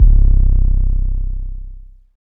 808 8 Sizzle.wav